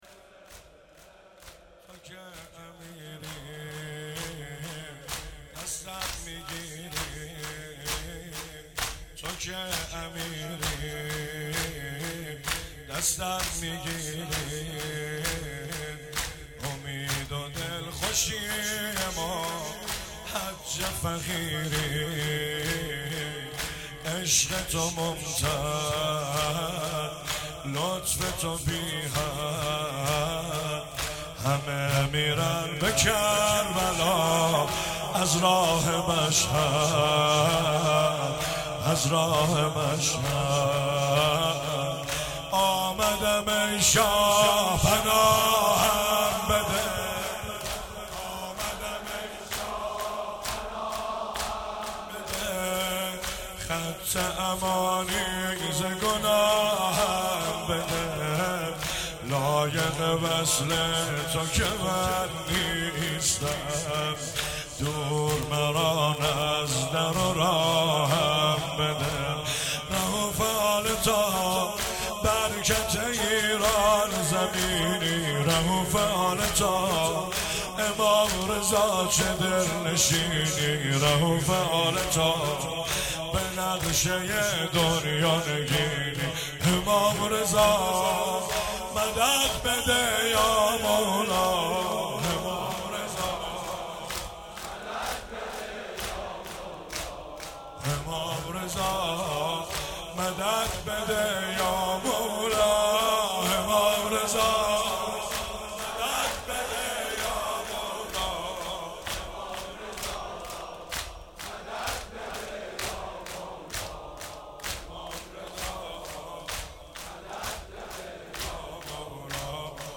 سرود